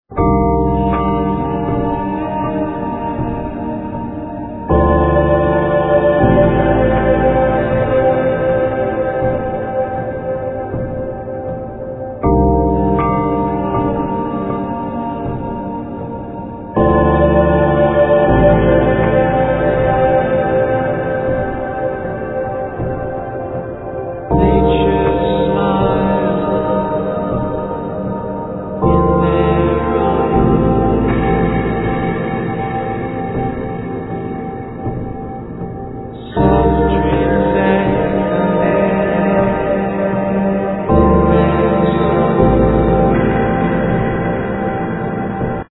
Synthesizer, Voice